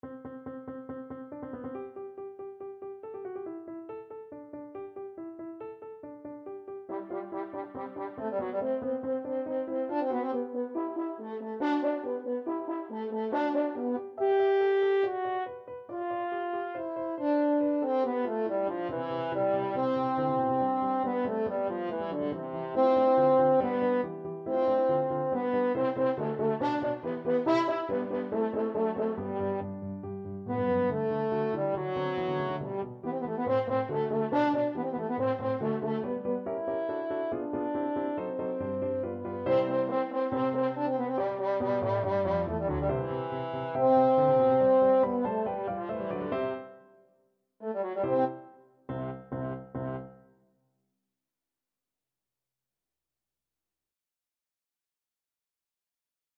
= 140 Allegro (View more music marked Allegro)
4/4 (View more 4/4 Music)
Classical (View more Classical French Horn Music)